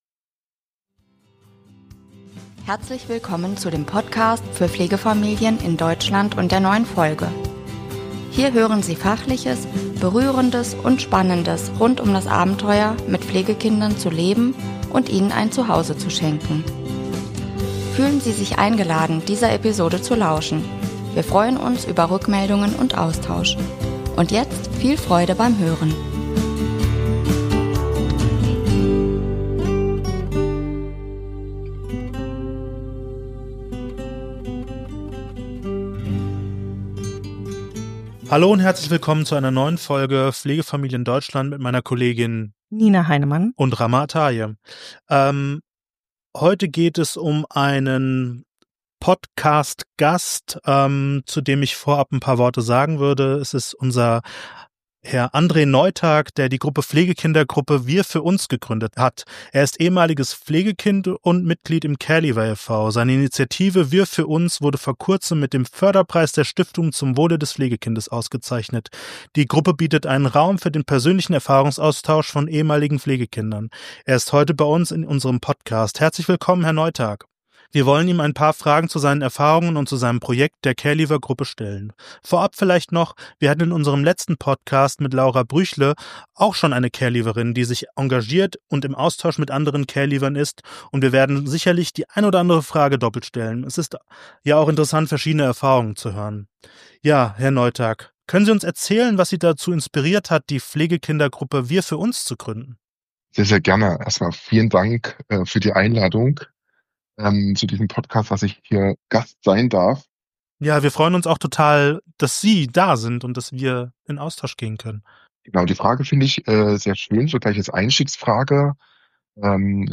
Die Gruppe bietet einen Raum für den persönlichen Erfahrungsaustausch von ehemaligen Pflegekindern. Er ist heute in unserem Podcast zu Gast: Herzlich Willkommen!